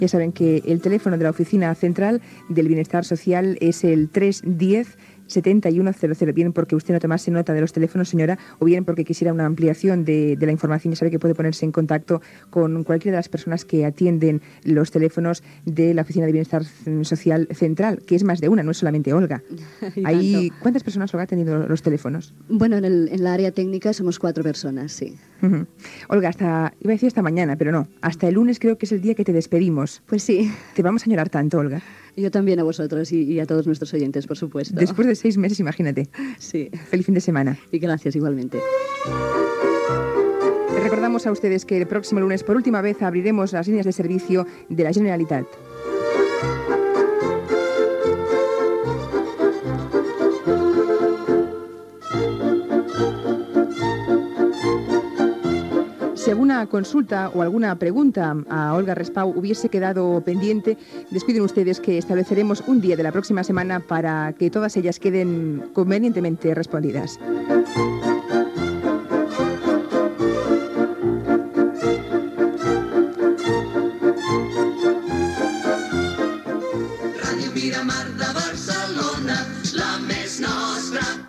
Indicatiu de l'emissora.
Entreteniment